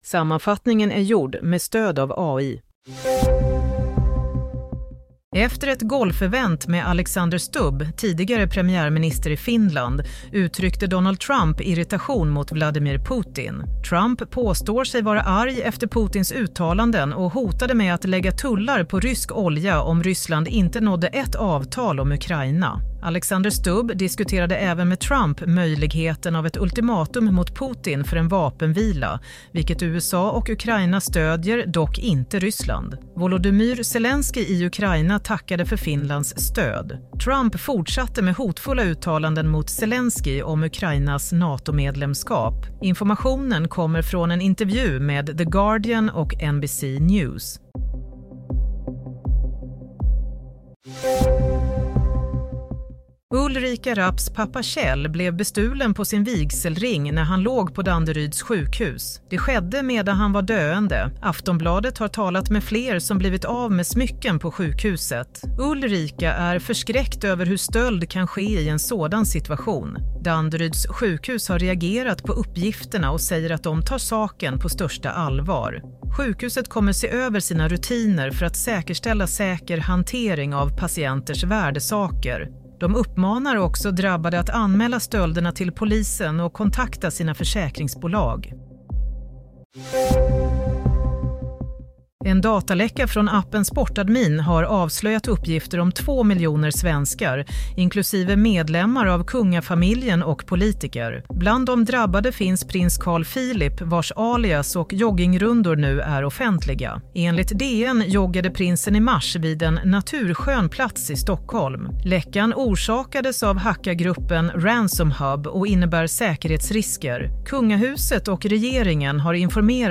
Nyhetssammanfattning – 31 mars 07:00